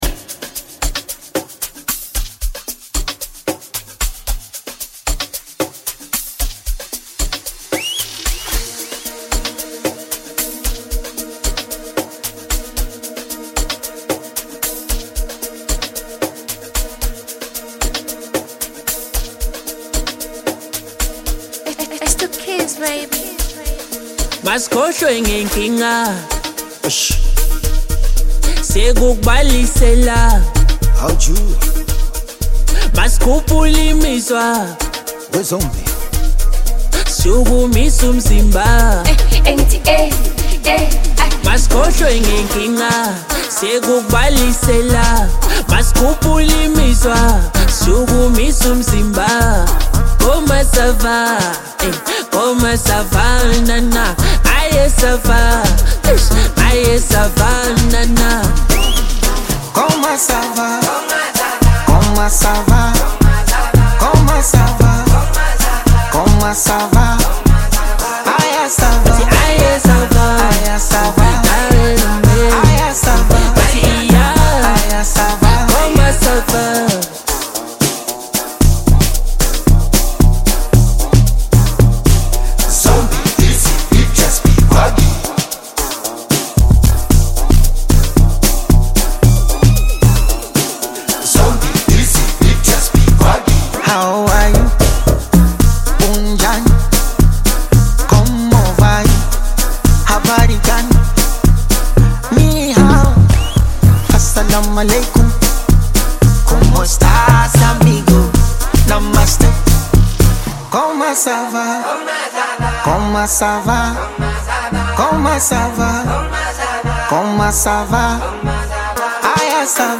Amapiano track